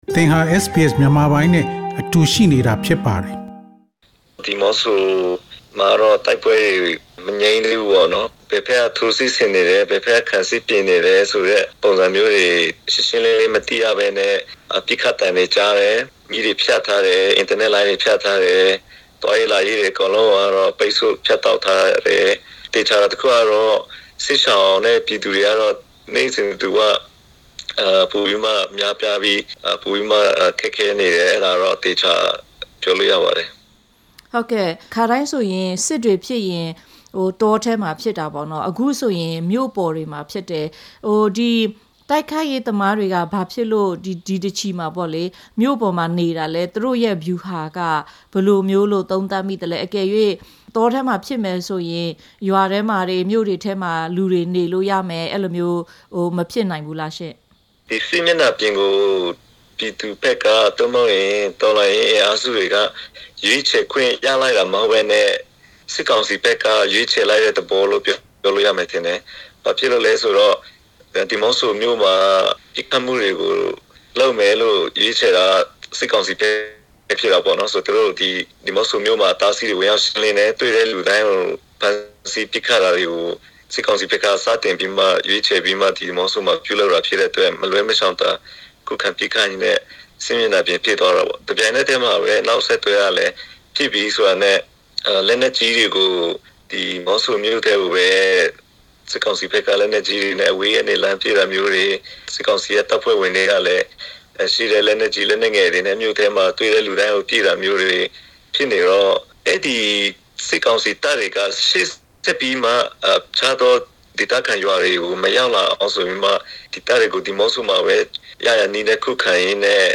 ဒီအကြောင်းတွေနဲ့ ပတ်သက်ပြီး လှုပ်ရှားသူ လူငယ်တစ်ဦးနဲ့ ဒီမနက်မှာ ဆက်သွယ်မေးမြန်းထားပါတယ်။